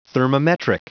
Prononciation du mot thermometric en anglais (fichier audio)
Prononciation du mot : thermometric